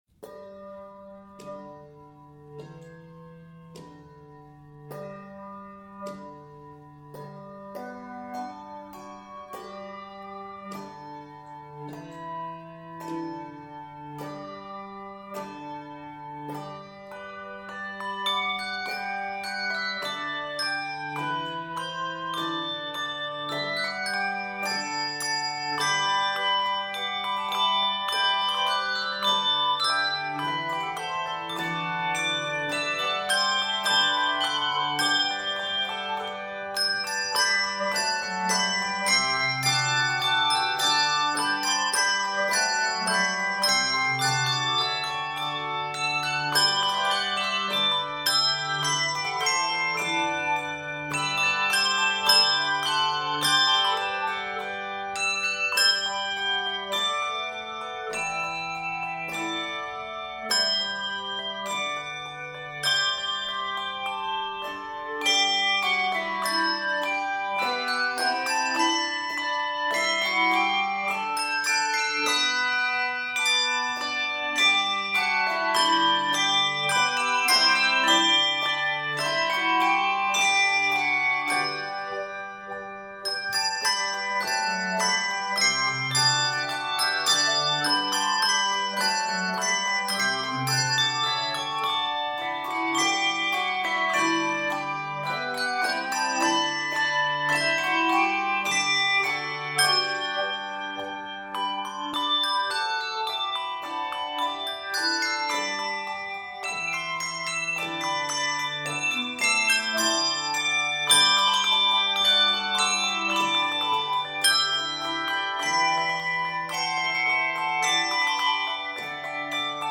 is 79 measures and is arranged in G Major